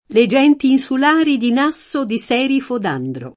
Serifo [S$rifo] top. (Gr.) — es. con acc. scr.: Le genti insulari di Nasso Di Sèrifo d’Andro [